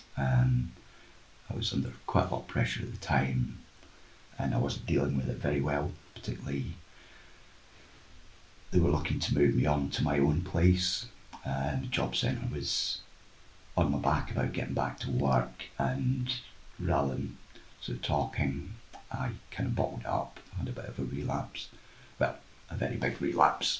Interviews by Community Links about real experiences of using support services to understand the good and bad impacts on individuals' lives.